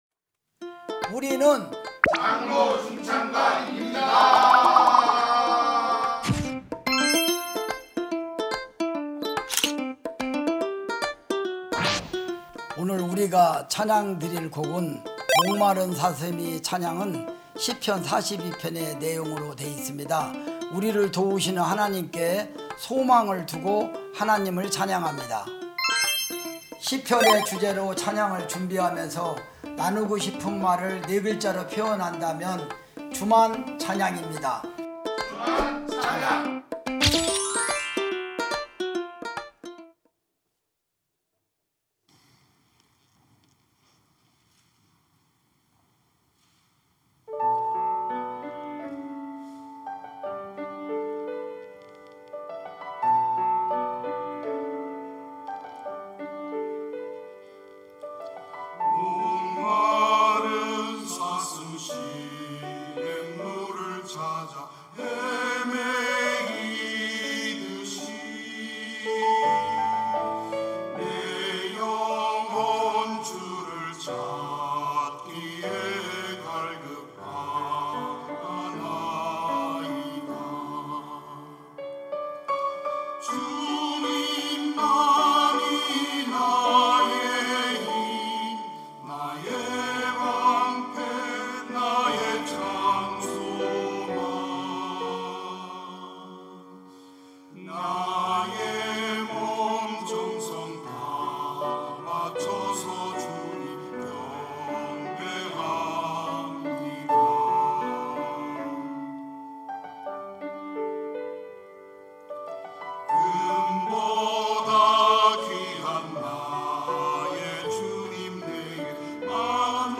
찬양대 장로